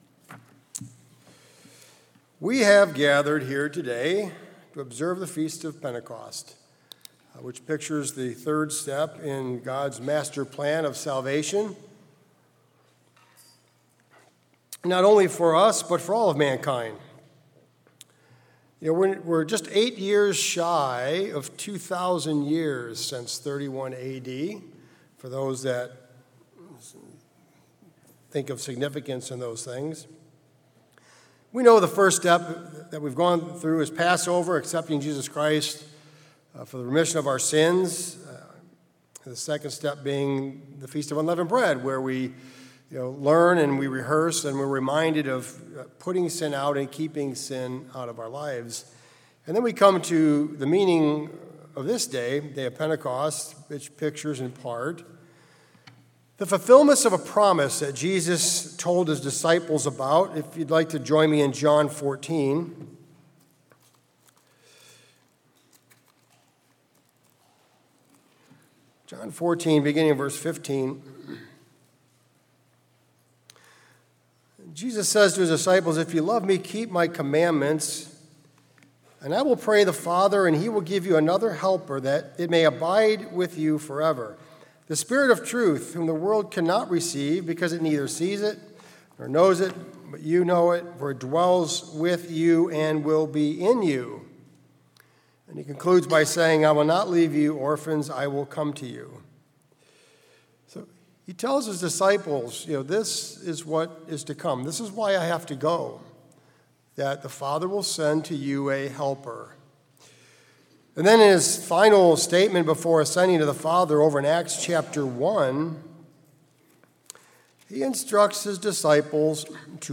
Sermons
Given in Columbus, OH